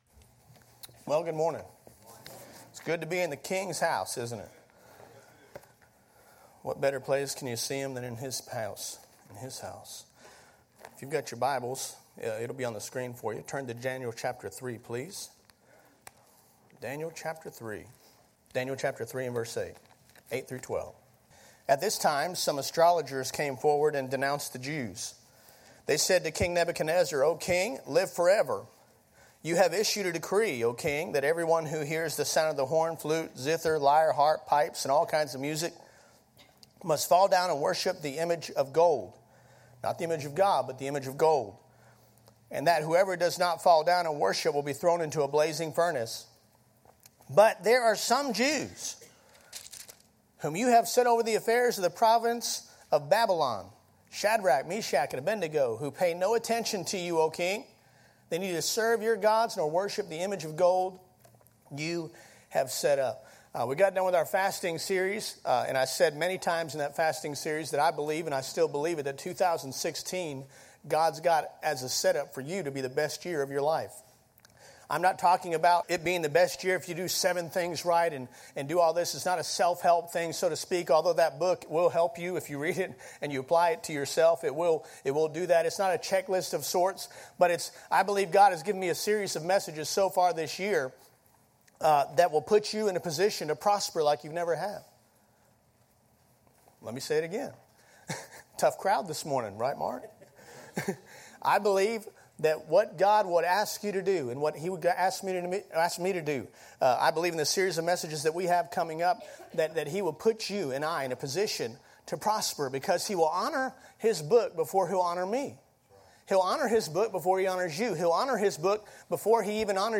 Raccoon Creek Baptist Church Sermons